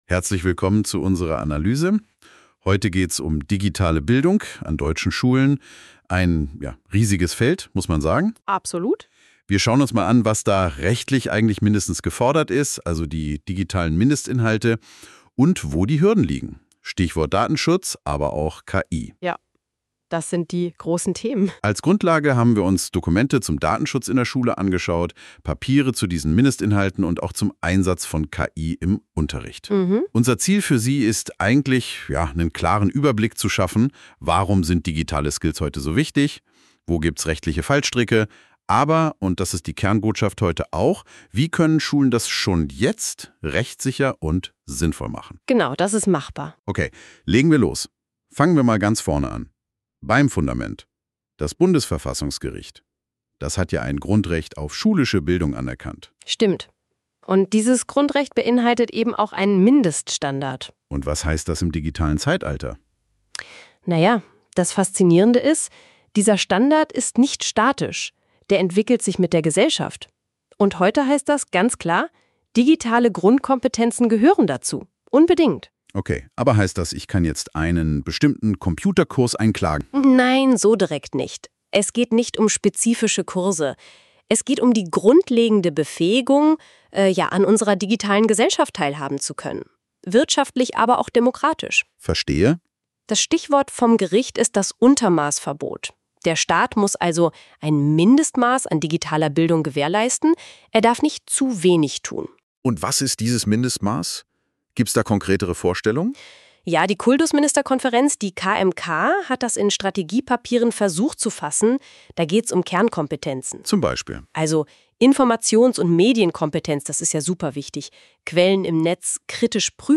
Um jetzt schon einen Einblick in diese spannenden Themen zu gewähren, hat das Team mit Hilfe von KI einen Podcast aus den im Projekt entstandenen Materialien erstellt. Im Podcast werden verschiedene Themengebiete beleuchtet und den Anwendern wichtige Hinweise zum rechtssicheren Umgang mit digitalen Bildungsangeboten an die Hand gegeben.